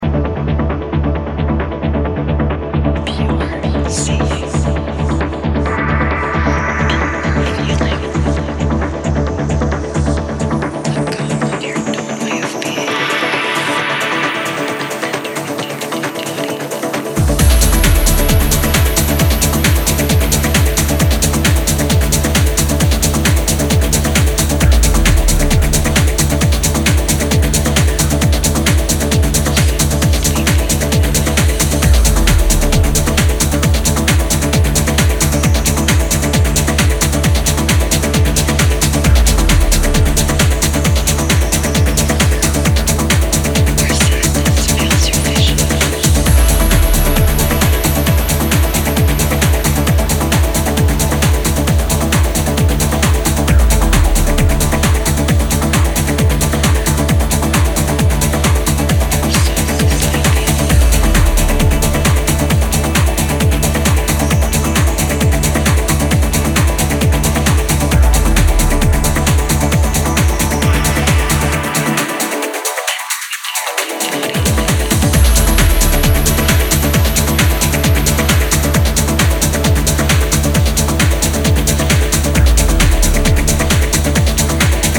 A split EP pairing two euphoric dance anthems
Romantic, ecstatic, and full of <3